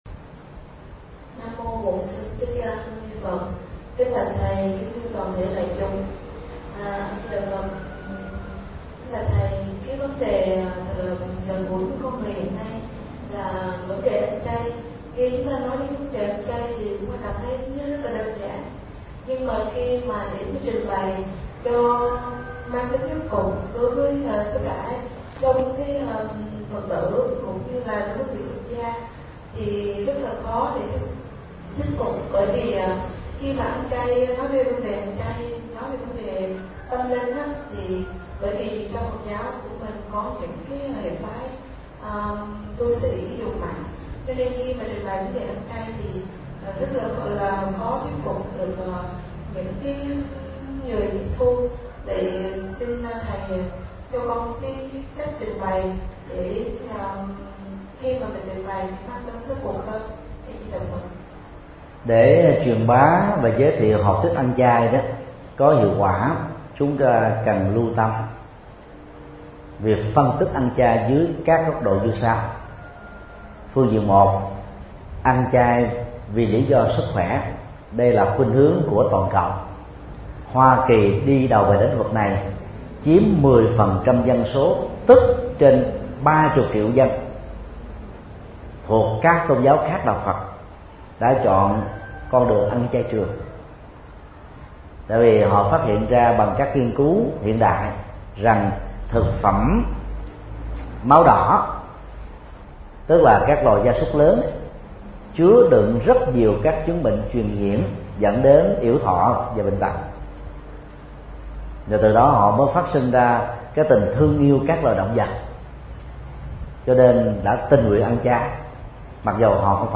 Nghe mp3 Vấn đáp